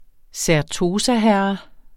Udtale [ sæɐ̯ˈtoːsa- ]